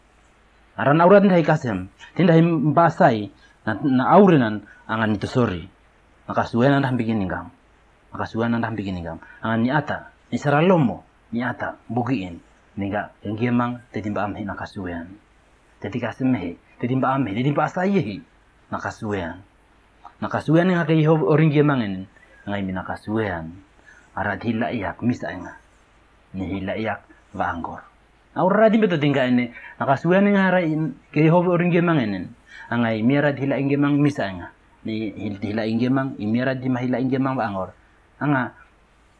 30 May 2016 at 9:06 am For a moment I thought the trills and central vowels hinted towards the Horn of Africa, but now seeing the comments and re-listening, I’m also more convinced that it’s more likely an Austronesian language.